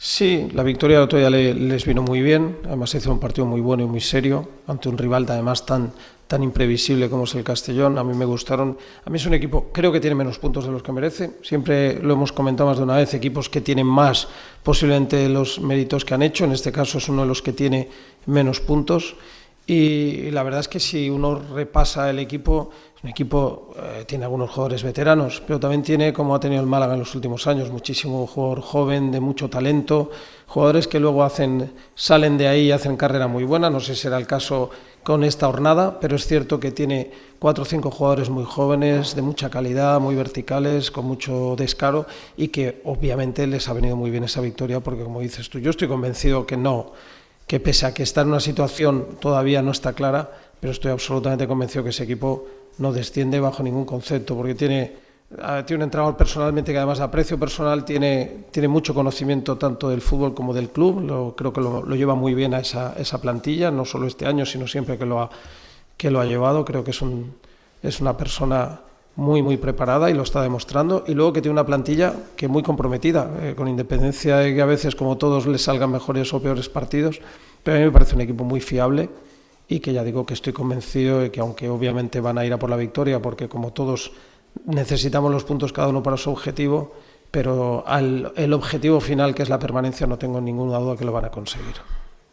El entrenador del Granada CF, Fran Escribá, ha comparecido ante los medios en sala de prensa con motivo de la previa del derbi que se disputará este sábado a las 18:30 horas en La Rosaleda.